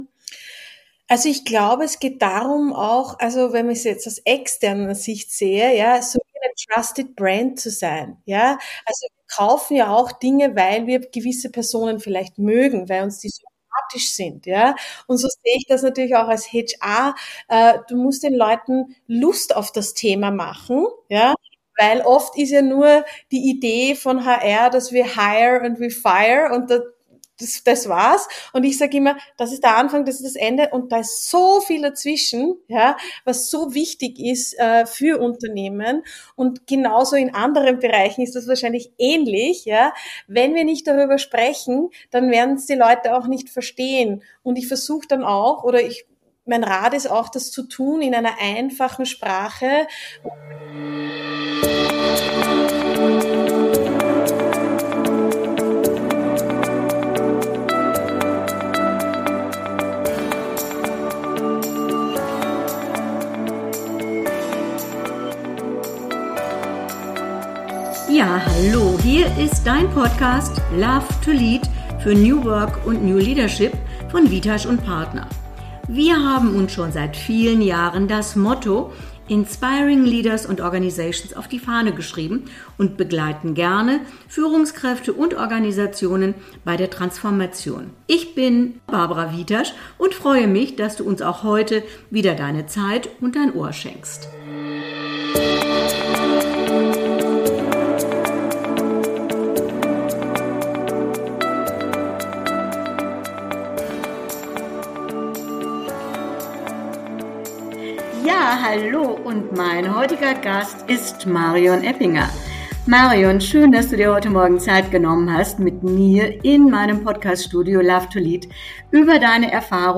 Ein mutiges Gespräch über Einfluss, Haltung und wie wir HR endlich die Bühne geben, die es verdient.